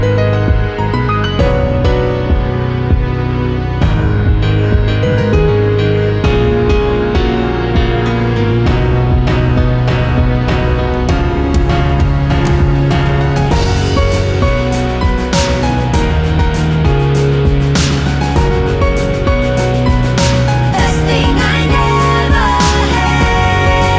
no Backing Vocals R'n'B / Hip Hop 4:18 Buy £1.50